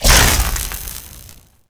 fx_laser_impact_libertyprime_02.wav